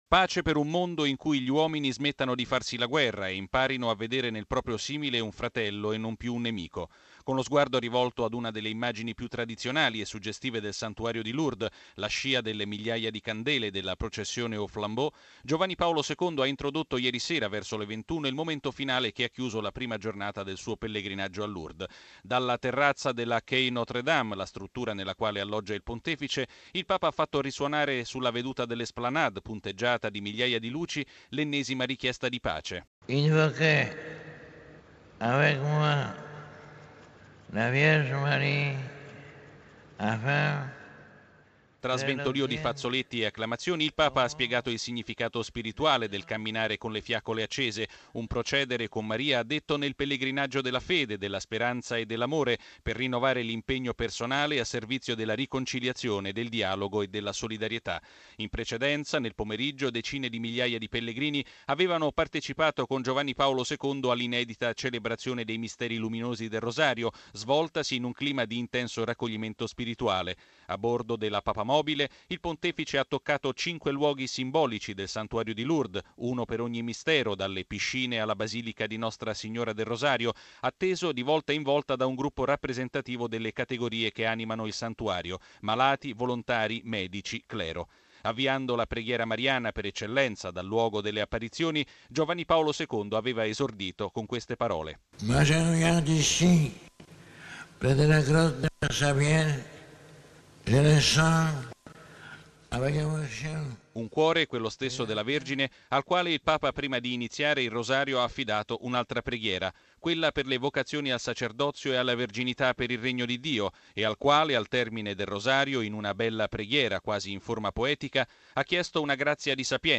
La sofferenza del Papa in primo piano in questa visita al Santuario di Lourdes, dove Giovanni Paolo II è giunto pellegrino tra i pellegrini, malato tra i malati, e tra migliaia di fedeli ha partecipato ieri pomeriggio - pure affaticato dal viaggio - alla Recita del Santo Rosario e più tardi in serata ha assistito alla processione aux flambeaux. Eventi carichi di emozione, come ci racconta ancora il nostro collega da Lourdes